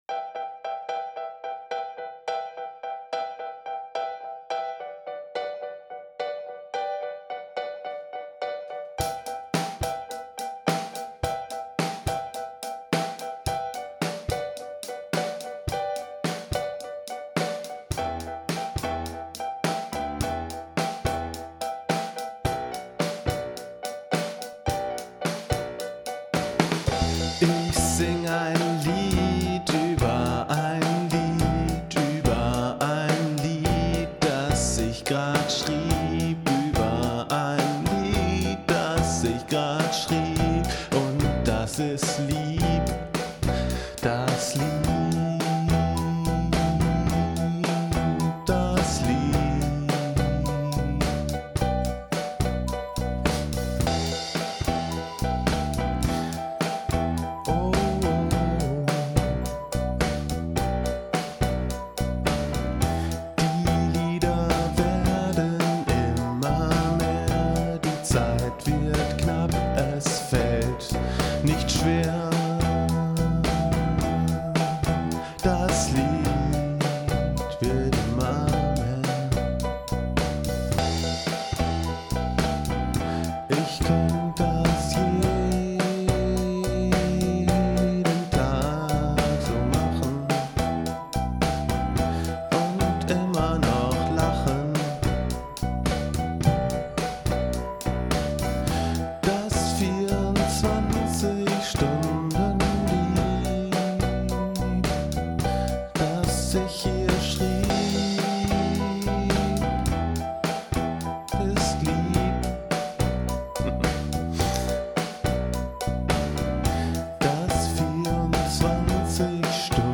Es ging mir vor allem darum, meiner Kreativität freien Lauf zu lassen und gleichzeitig mein kleines Heimstudio auszuprobieren.
• Alles wurde an einem Tag geschrieben, gespielt, aufgenommen und produziert.